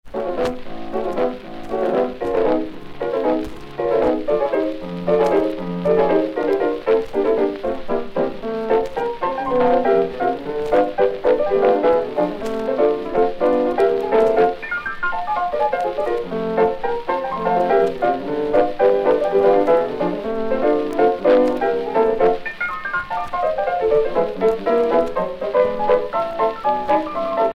danse : charleston
Pièce musicale éditée